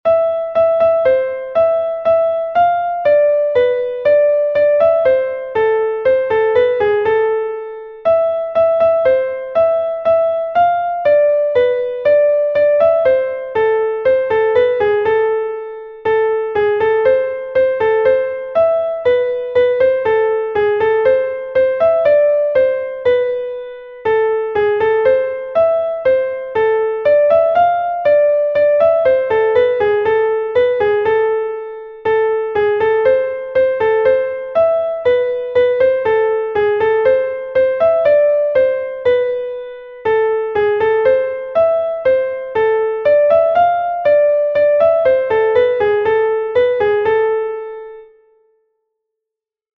Kas a-barh